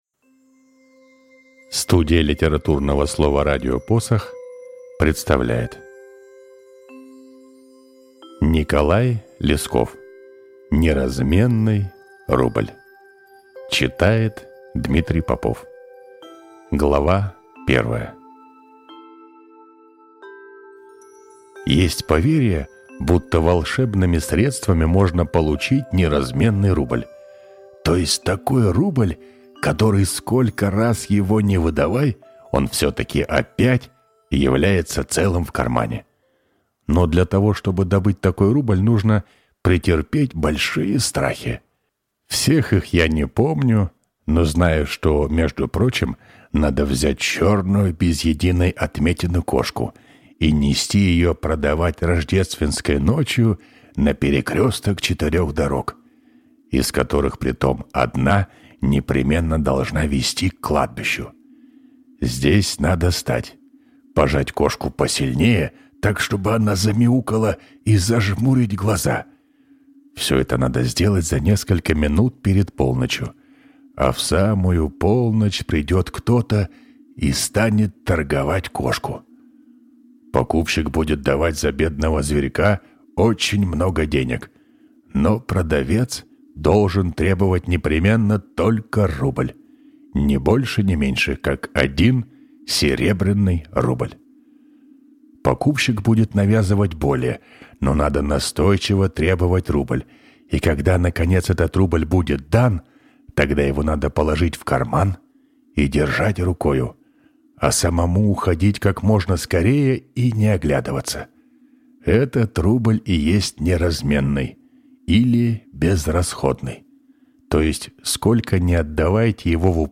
Аудиокнига Неразменный рубль | Библиотека аудиокниг